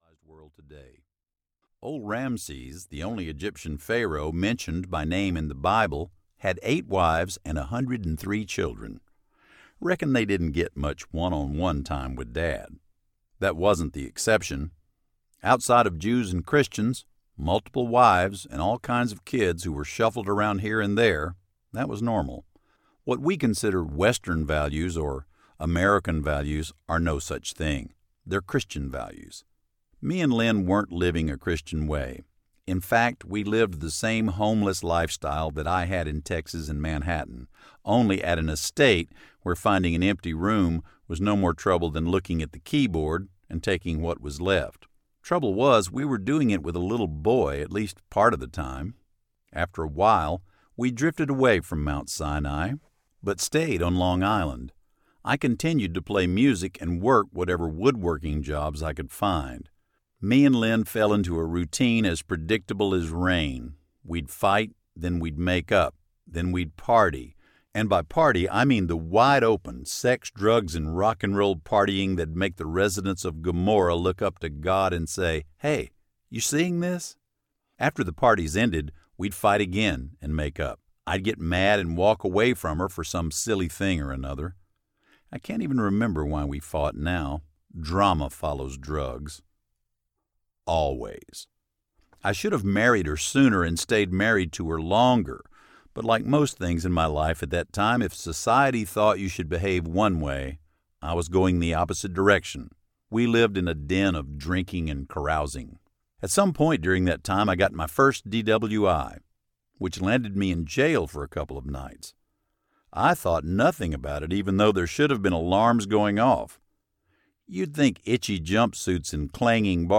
Going Down to the River Audiobook
9.4 Hrs. – Unabridged